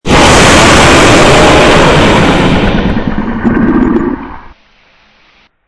Download Dragon sound effect for free.
Dragon